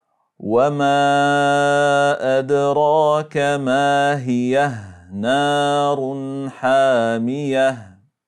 Enligt Ĥafş från ‘Aşim (في روايةِ حفصٍ عن عاصمٍ) läses det med en sukun vid både fortsättnig och stopp, som i: